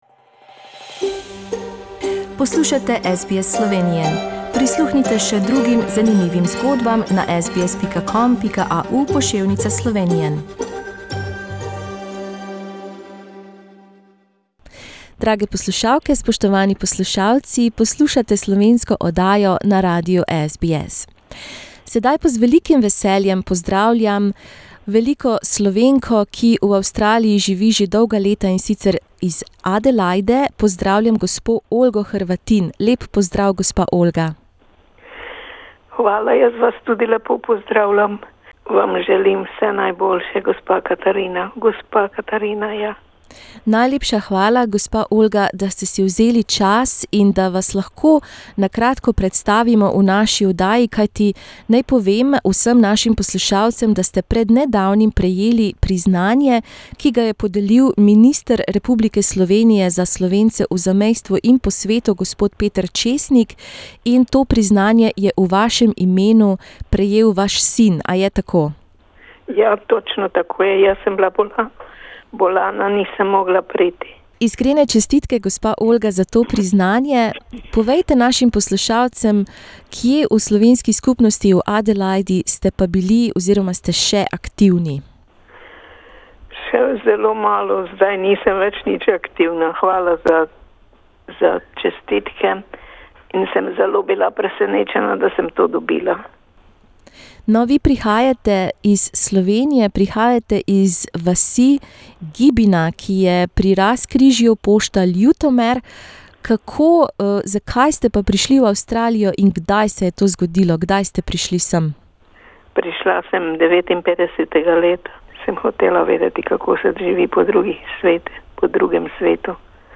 V tem pogovoru boste slišali delček njene življenjske zgodbe.